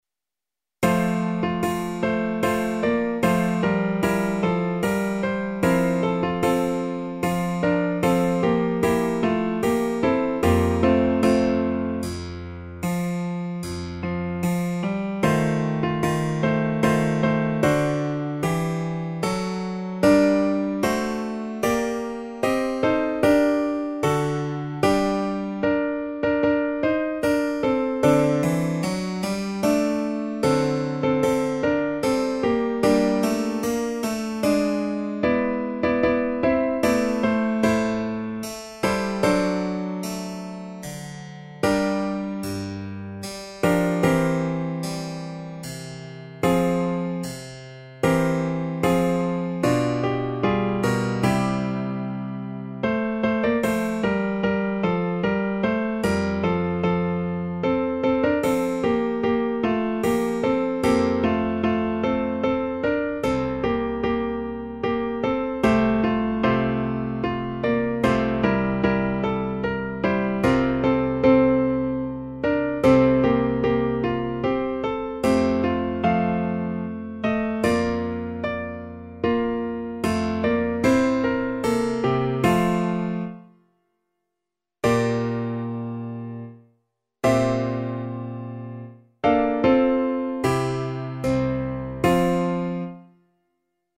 Para Coro Solo
(con parte opcional de Continuo)